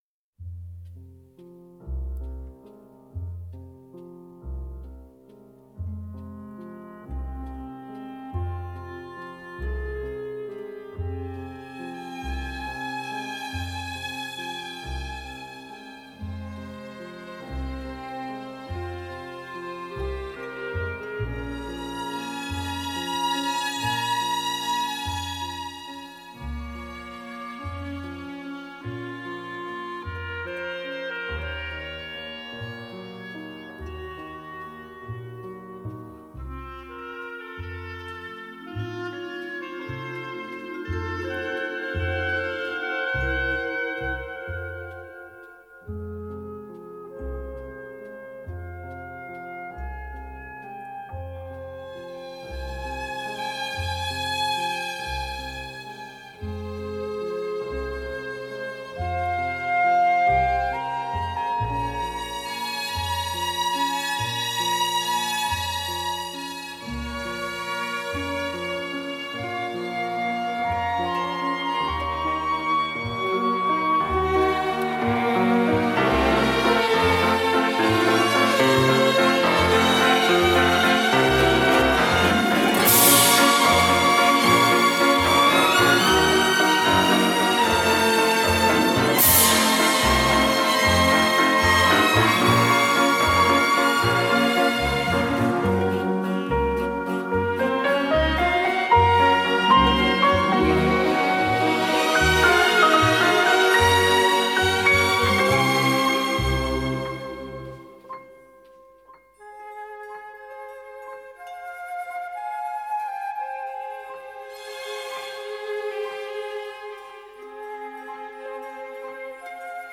вальс